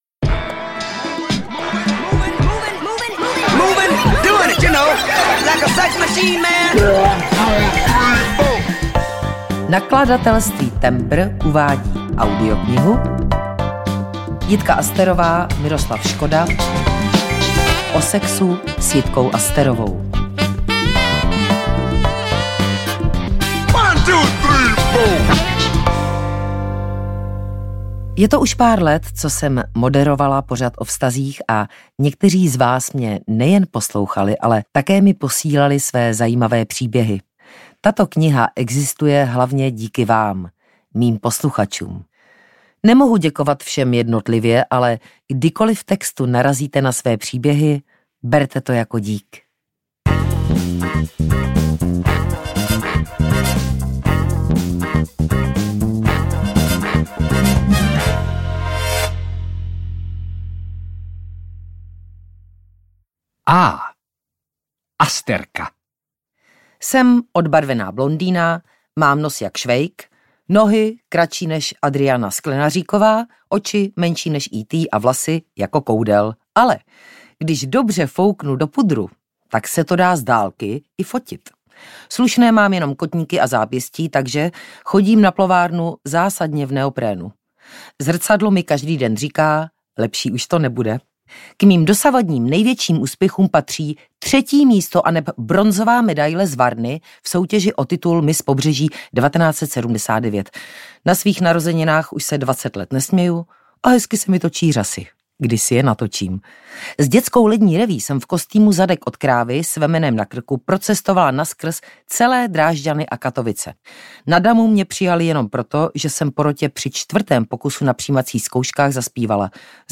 O sexu s Jitkou Asterovou audiokniha
Ukázka z knihy